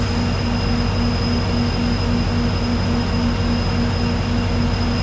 diesel-loop-3.ogg